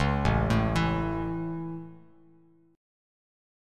A Chord
Listen to A strummed